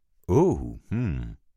Звуки согласия
Все файлы записаны четко, без фоновых шумов.
Звук да с закрытым ртом